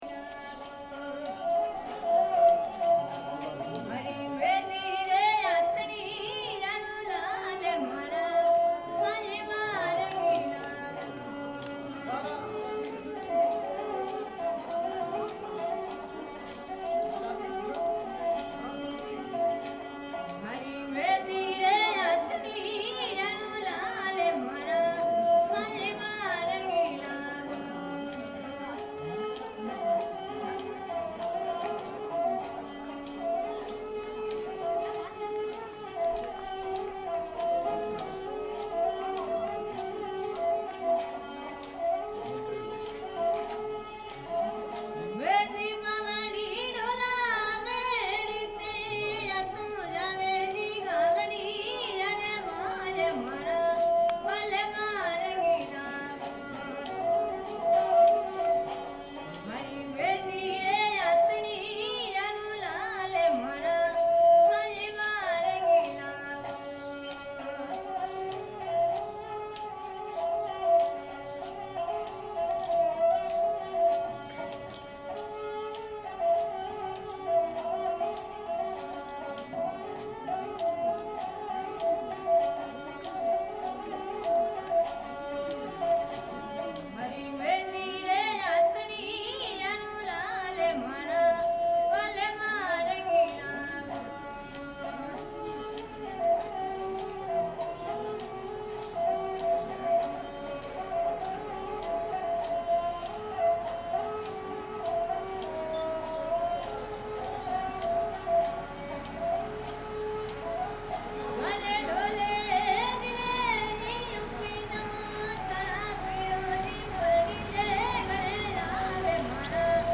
musicien_fort.mp3